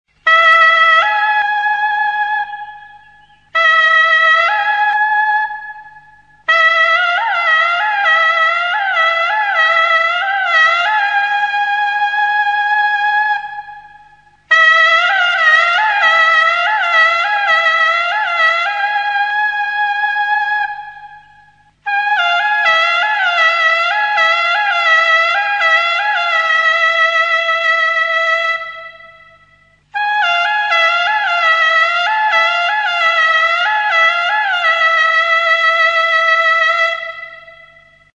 Instrumentos de músicaSUNPRINUA
Aerófonos -> Lengüetas -> Doble (oboe)
EUROPA -> EUSKAL HERRIA
Hurritza makila batetik ateratako azal zintarekin egindako oboea da.
Tonu aldaketarako 2 zulo ditu aurrekaldeko behealdean.